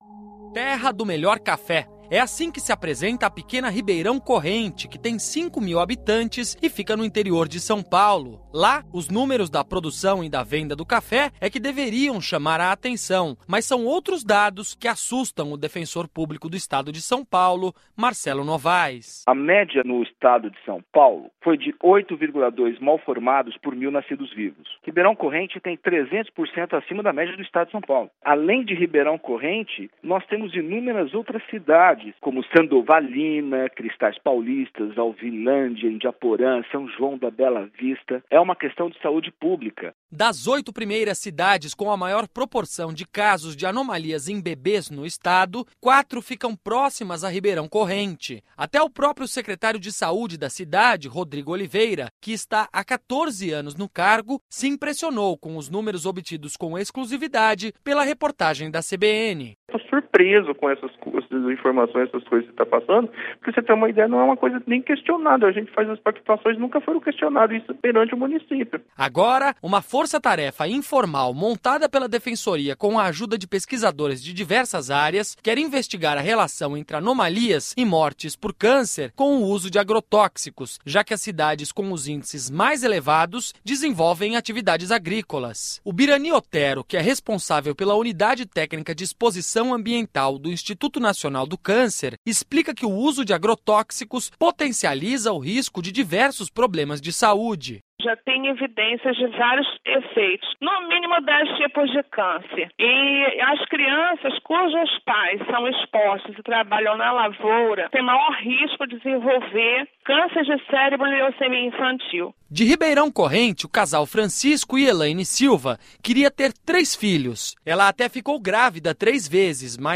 Promotor e Defensor destacam a importância da sociedade civil na questão: